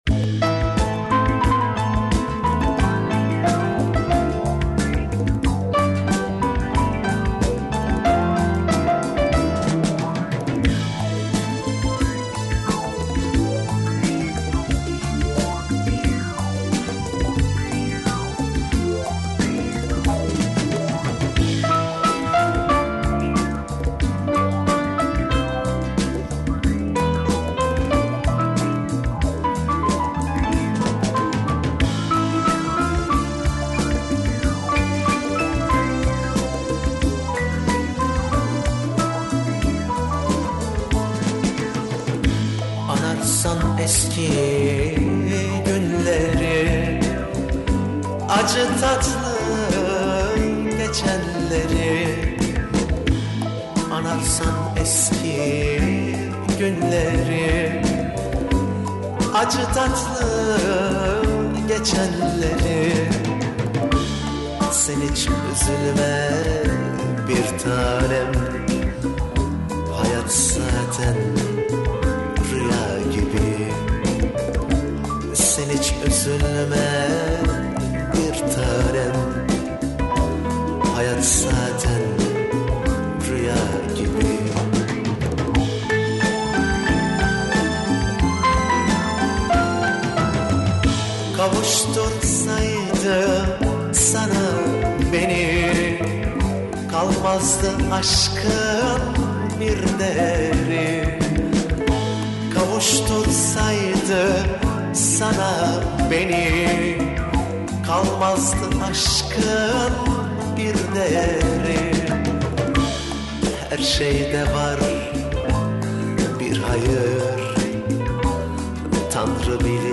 turkish